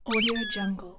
دانلود افکت صوتی جیغ اسباب بازی
Tiny Squeaky Toy Slow 2 royalty free audio track is a great option for any project that requires cartoon sounds and other aspects such as an accessory, animal and ball.
Sample rate 16-Bit Stereo, 44.1 kHz
Looped No